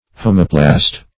Search Result for " homoplast" : The Collaborative International Dictionary of English v.0.48: Homoplast \Hom"o*plast\, n. (Biol.)
homoplast.mp3